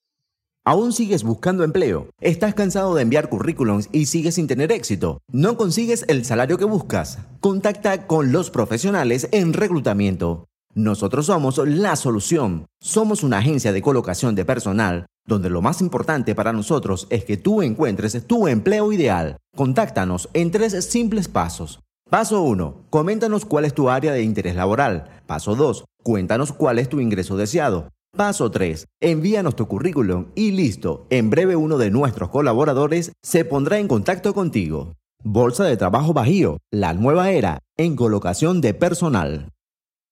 Voz para video corporativo bolsa de trabajo bajio